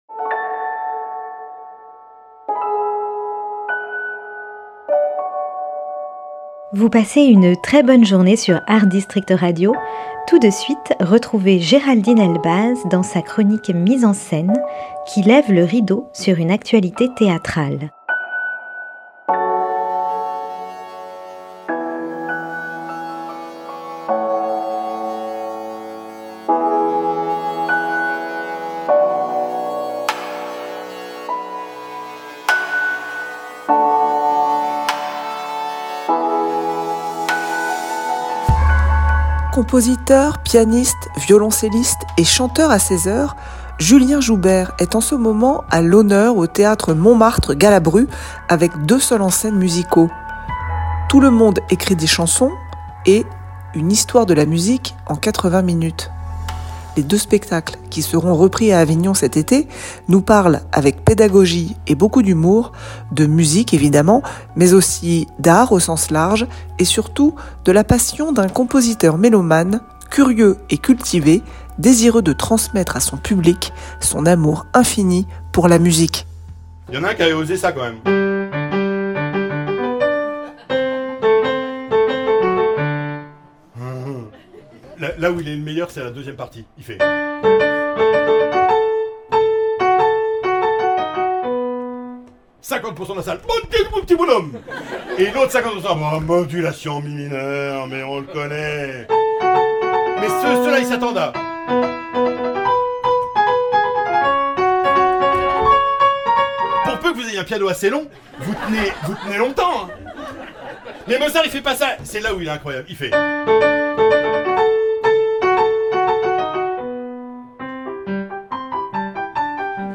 Chronique théâtrale